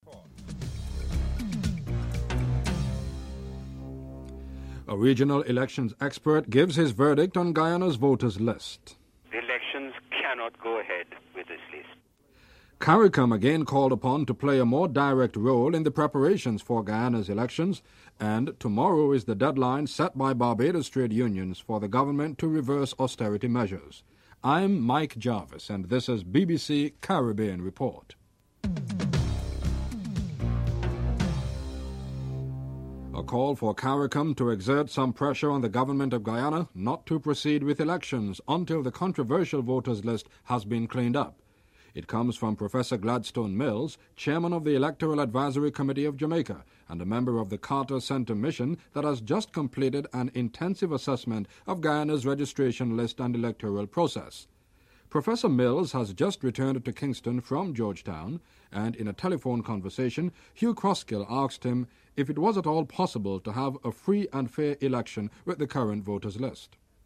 The British Broadcasting Corporation
1. Headlines (00:00-00:34)